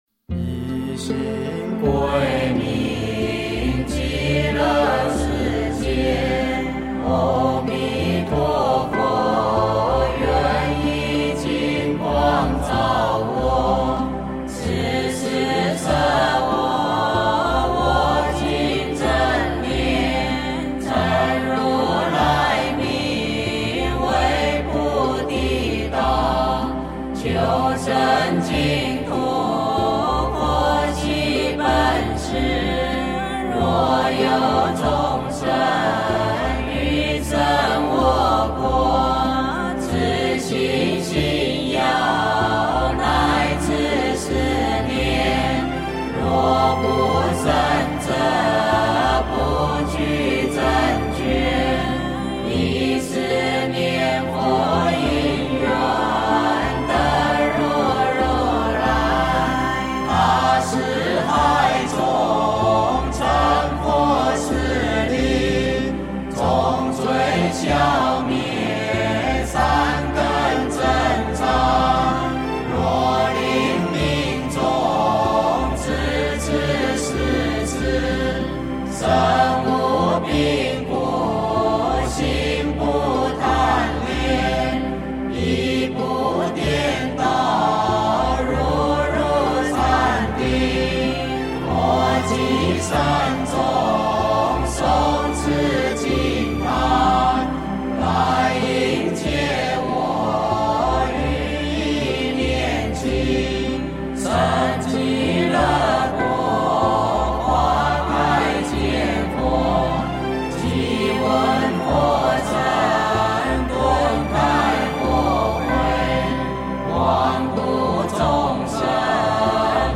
净土发愿文--佛光山梵呗团 经忏 净土发愿文--佛光山梵呗团 点我： 标签: 佛音 经忏 佛教音乐 返回列表 上一篇： 妙法莲华经观世音菩萨普门品--如是我闻 下一篇： 字母赞--如是我闻 相关文章 八十八佛大忏悔文-闽南语--圆光佛学院众法师 八十八佛大忏悔文-闽南语--圆光佛学院众法师...